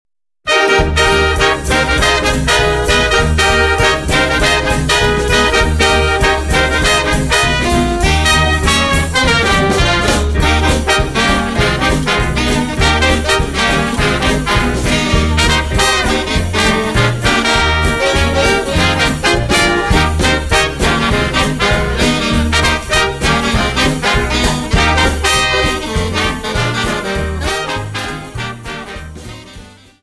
Quickstep 50 Song